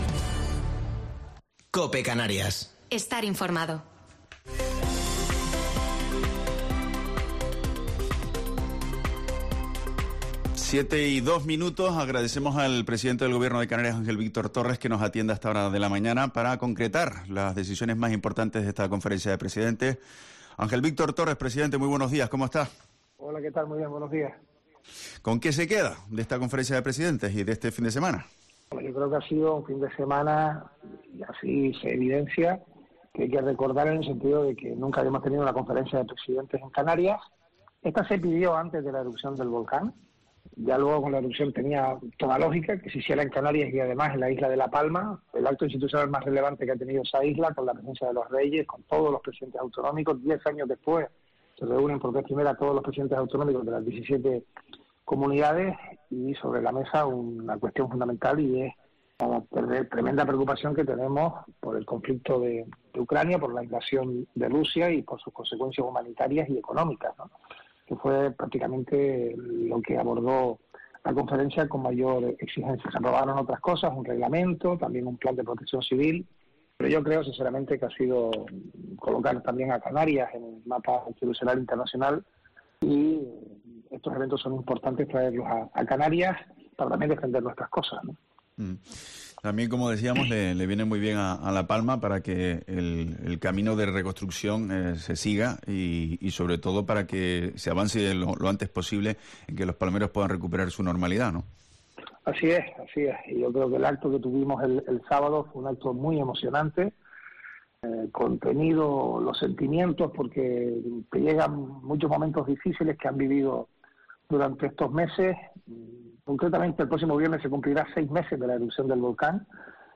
Entrevista a Ángel Víctor Torres en 'La Mañana en Canarias' (14/3/21)